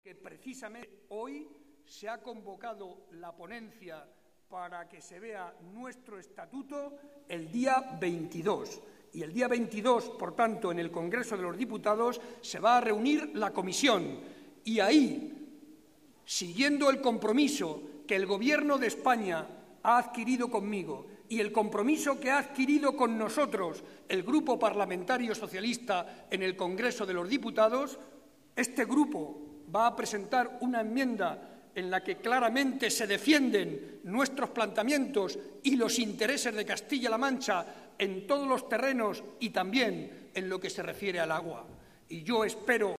Barreda que hacía estas declaraciones en el marco de la cena que con motivo de la Navidad celebra tradicionalmente el PSOE de Guadalajara, explicó además que el Grupo Parlamentario Socialista presentará una enmienda en la que se defenderán todos los planteamientos que reivindica nuestra Comunidad Autónoma, entre ellos el agua.
Audio Barreda cena navidad psoe gu